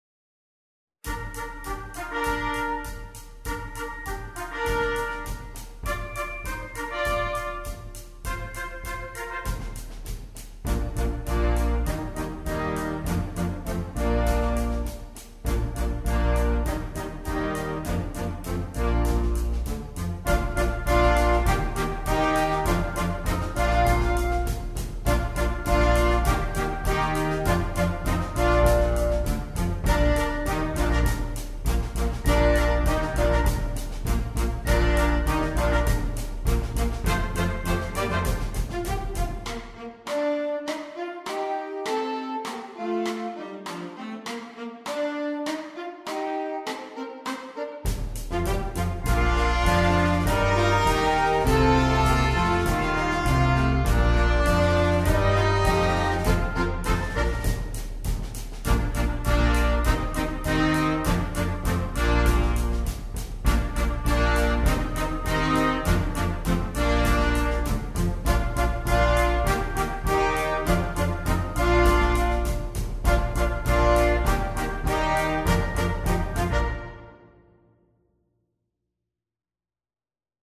It is very playable by the 2nd year band.